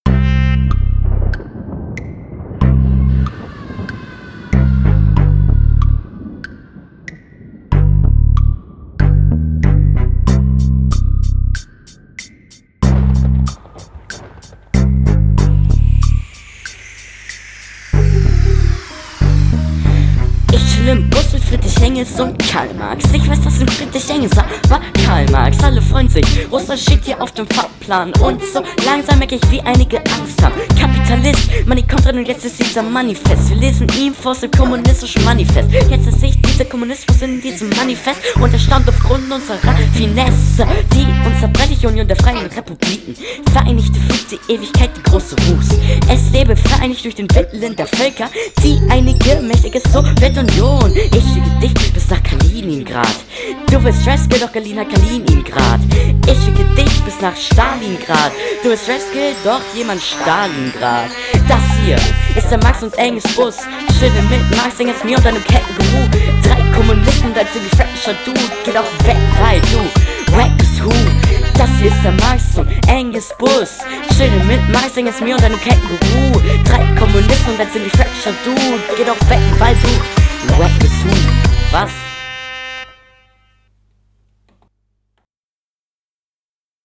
he/she außerdem habe ich KEINEN Pitch.